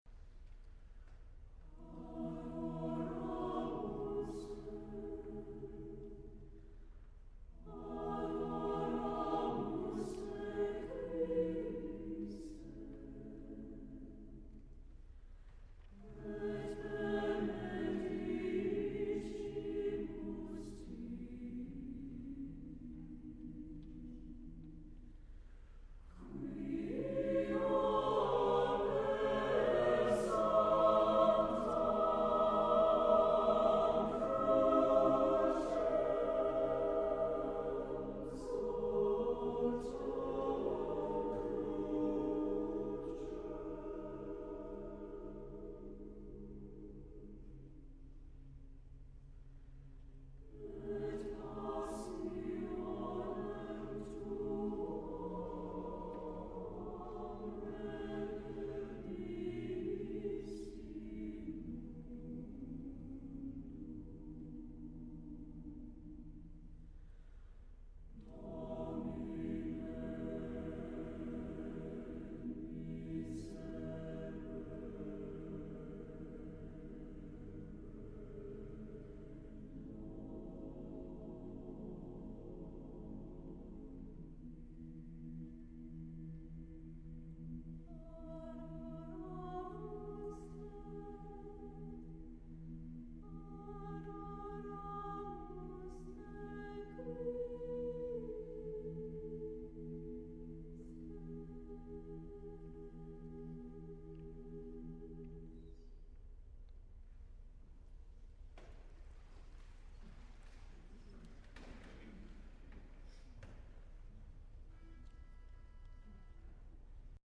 Accompaniment:      A Cappella
Music Category:      Choral
AÊbeautiful,ÊintimateÊsetting of thisÊwell-known Latin text.